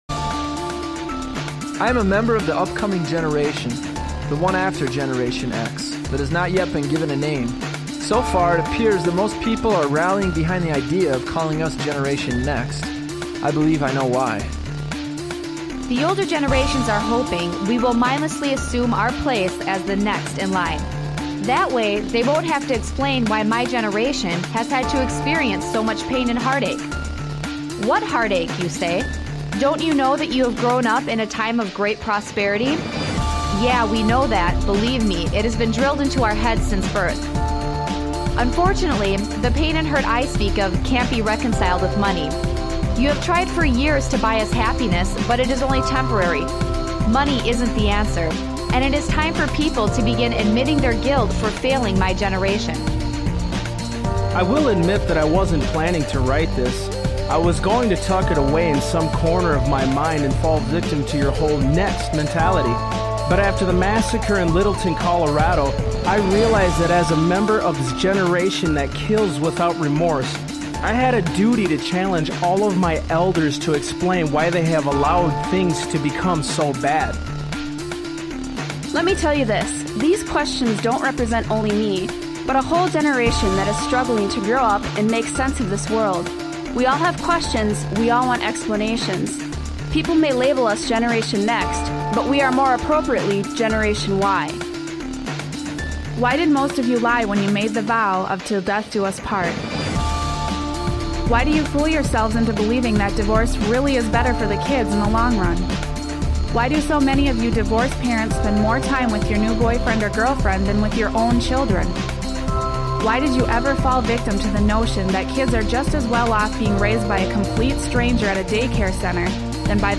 Talk Show Episode, Audio Podcast, Sons of Liberty Radio and It Doesn't Stop There on , show guests , about It Doesn't Stop There, categorized as Education,History,Military,News,Politics & Government,Religion,Christianity,Society and Culture,Theory & Conspiracy